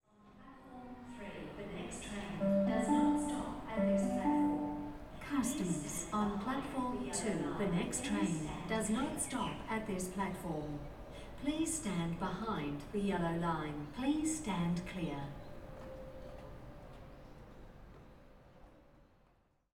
announcements.ogg